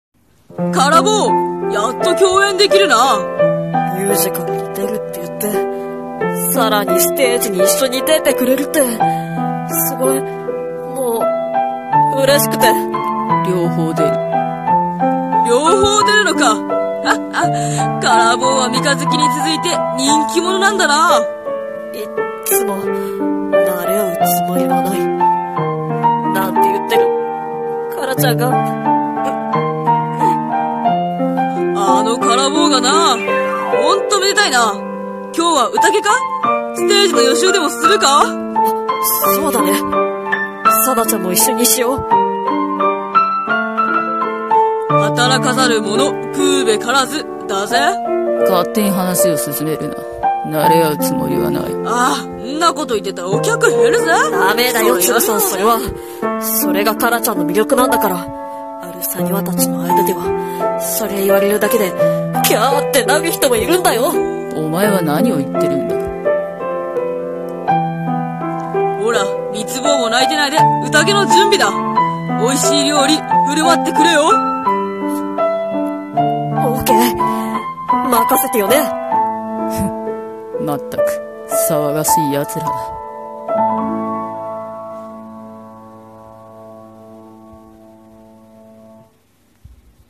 【声劇】伽羅ちゃんおめでとう【刀剣乱舞 伊達組】 燭台切光忠